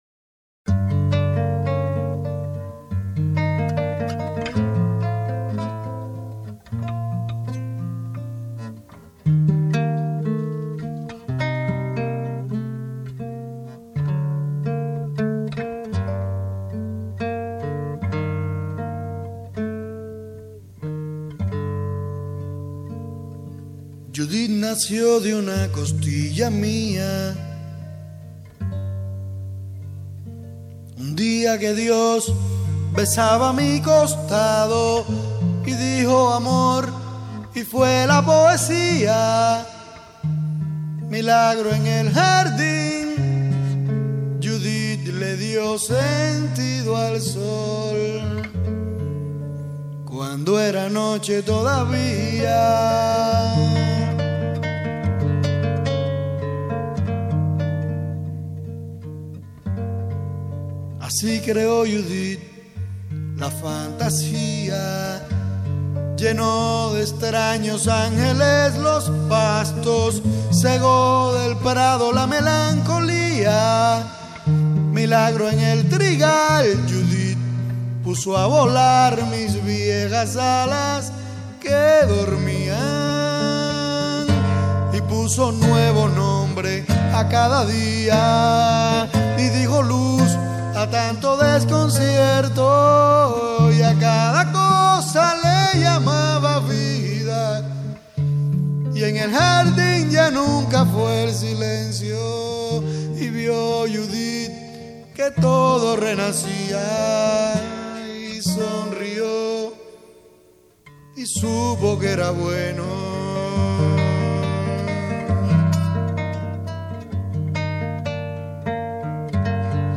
En 1994 grabé estas canciones en casa de un amigo en Cuba.